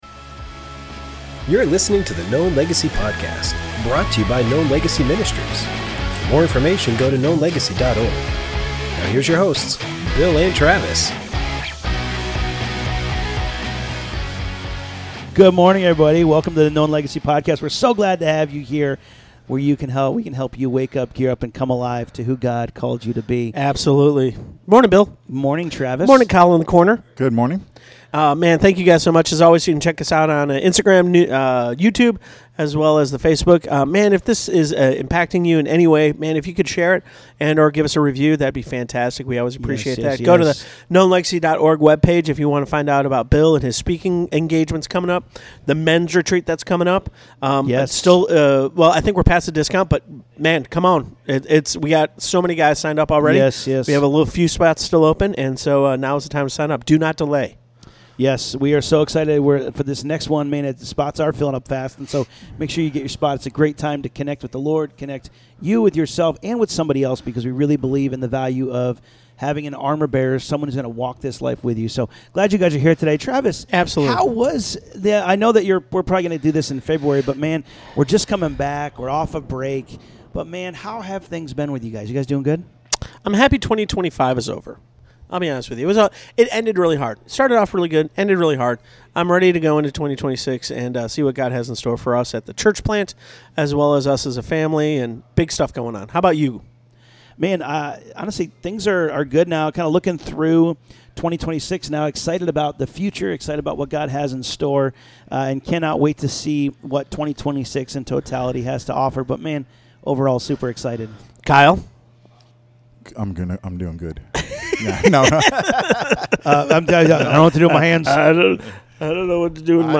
What would you say if you could sit down with your younger self? In this episode, the guys have an honest and reflective conversation, sharing the wisdom they wish they had known as teenagers and young adults.